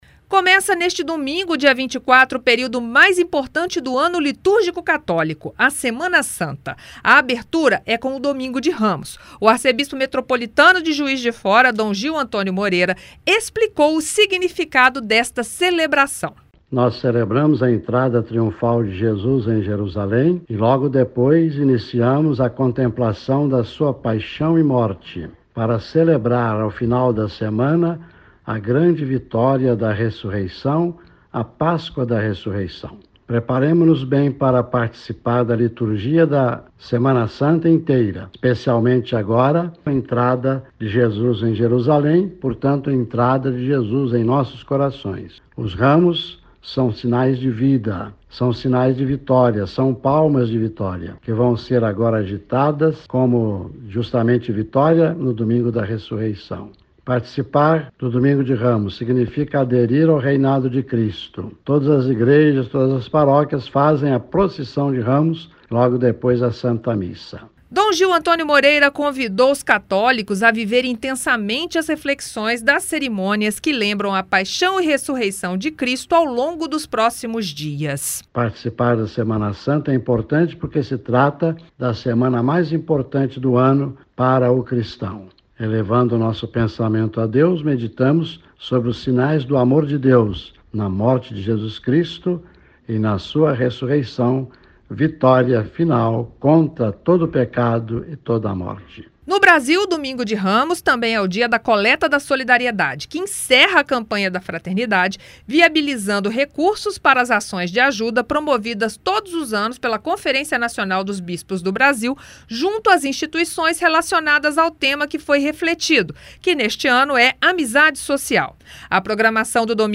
Em Juiz de Fora, o Arcebispo Metropolitano Dom Gil Antônio Moreira lembrou que o Domingo de Ramos marca a “entrada de Jesus em nossos corações” e convida os católicos a viverem bem as cerimônias da Semana Santa. Ouça a reportagem.